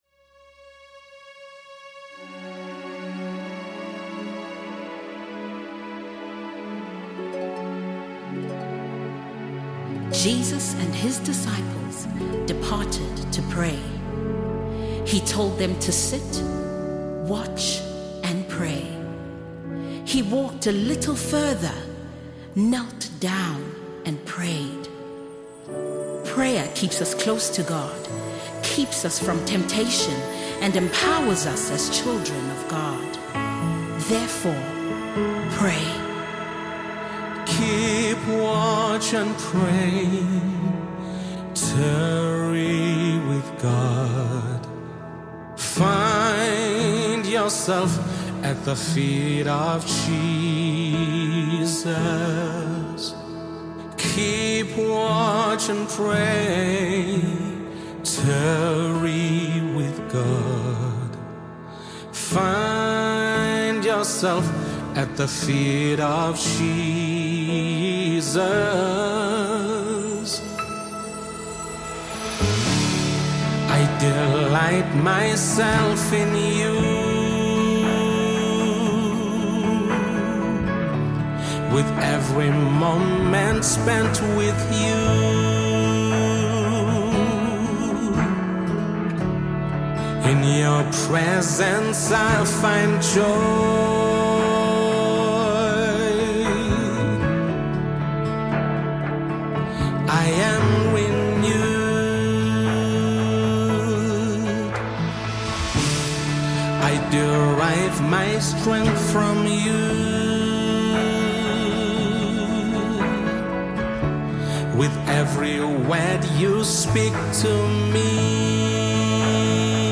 Home » Gospel